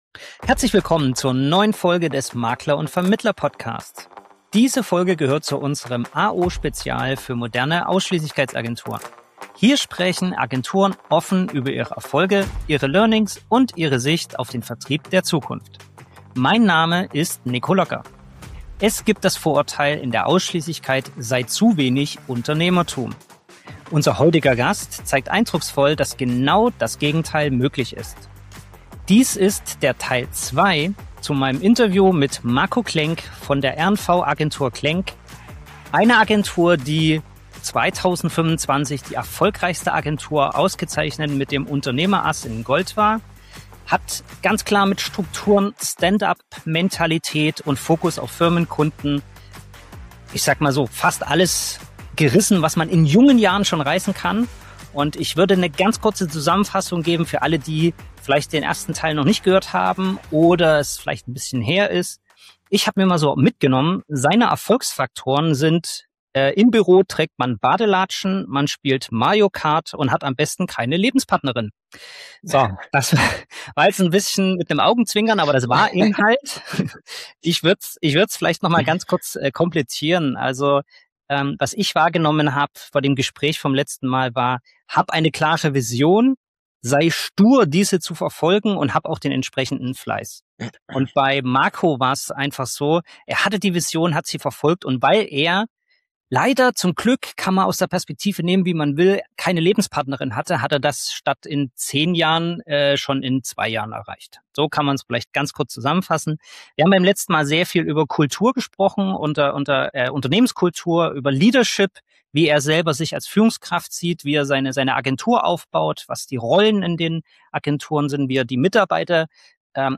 Darüber hinaus erhalten die Hörer exklusive Einblicke in Motivations- und Bonifikationssysteme, die Rolle des Versicherers als Sparringspartner und die Bedeutung von Netzwerken und Investitionen im Agenturgeschäft. Freuen Sie sich auf einen praxisnahen, offenen Austausch mit wertvollen Learnings, sowohl für Agenturisten als auch für Versicherungsgesellschaften, die die Zukunft des Vertriebs aktiv mitgestalten wollen.